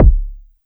Concrete Sub Kick OS.wav